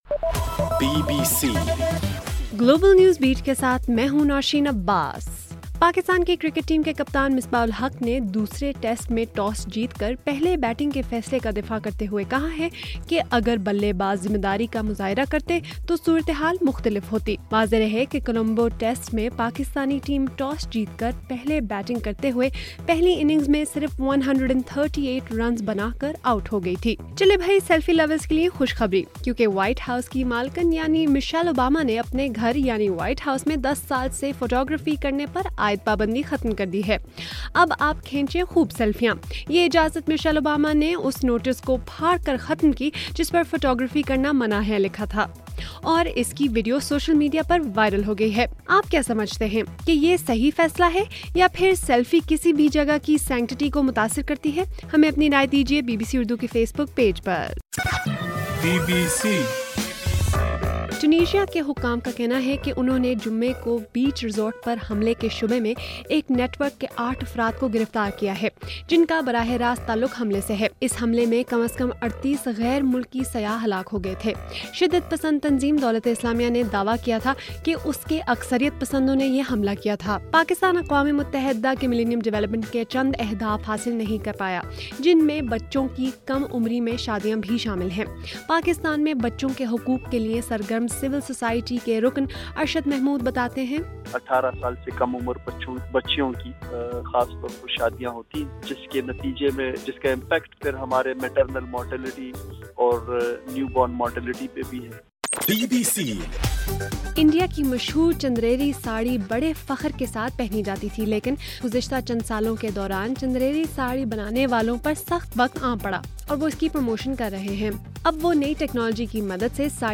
جولائی 2: رات 9 بجے کا گلوبل نیوز بیٹ بُلیٹن